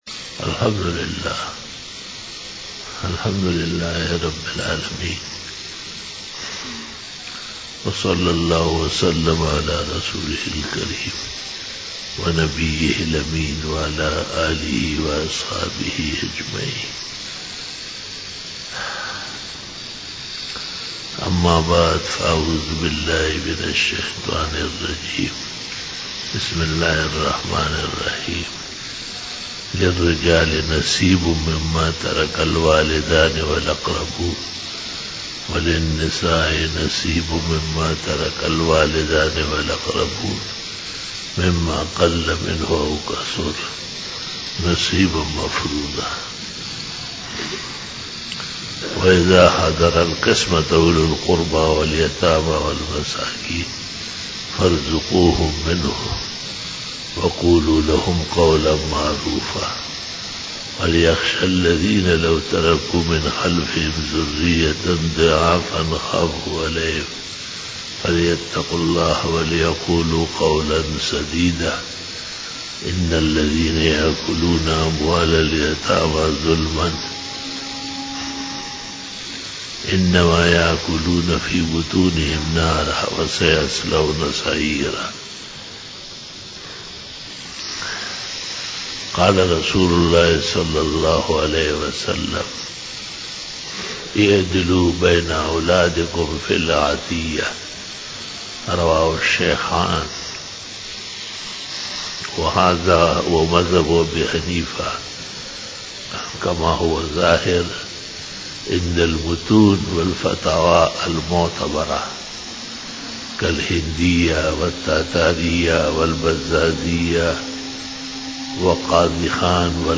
02 BAYAN E JUMA TUL MUBARAK 10 JANUARY 2020 (14 Jamadal Uola 1441H)
Khitab-e-Jummah